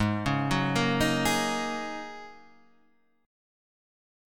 G#9sus4 chord {4 4 4 3 4 4} chord